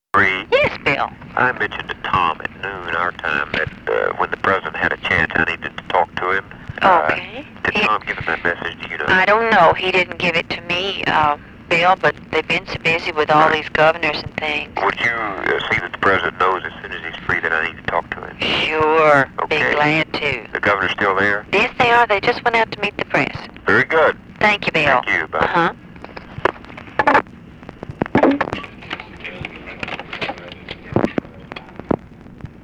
Conversation with BILL MOYERS?
Secret White House Tapes | Lyndon B. Johnson Presidency Conversation with BILL MOYERS?